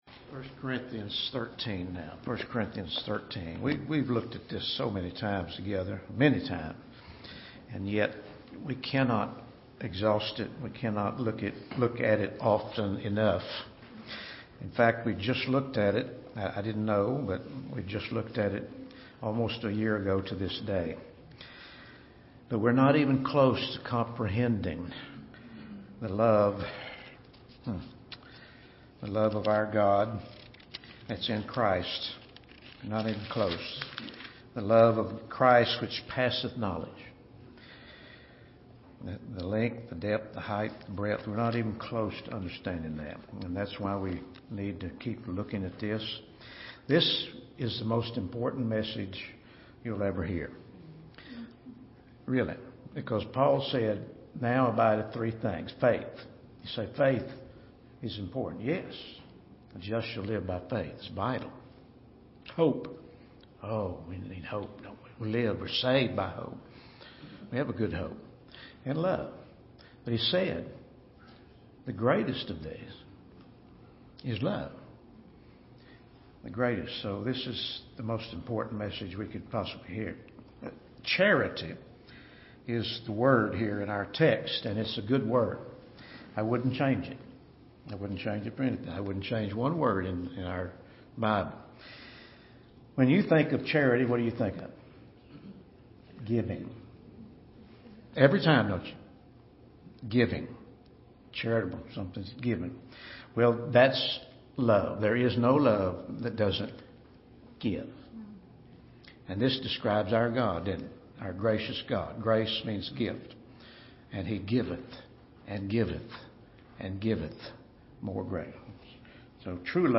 The Greatest Thing of All | SermonAudio Broadcaster is Live View the Live Stream Share this sermon Disabled by adblocker Copy URL Copied!